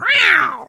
kit_atk_vo_02.ogg